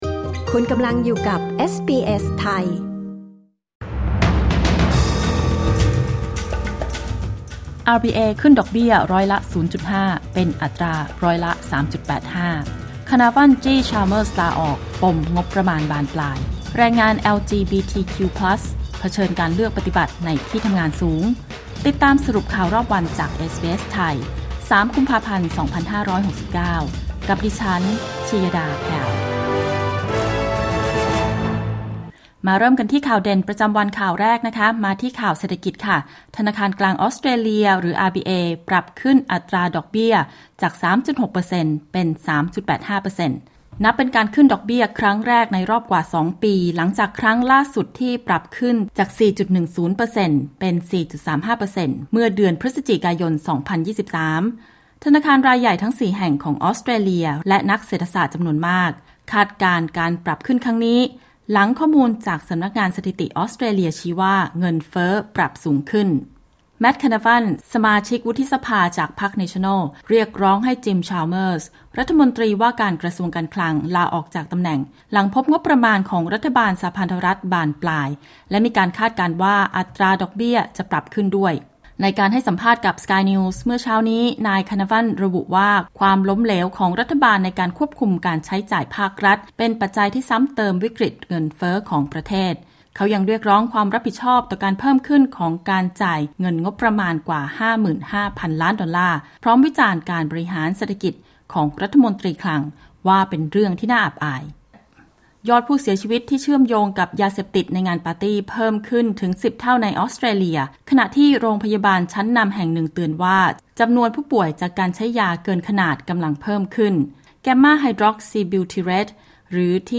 สรุปข่าวรอบวัน 3 กุมภาพันธ์ 2569